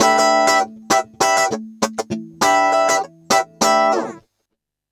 100FUNKY08-L.wav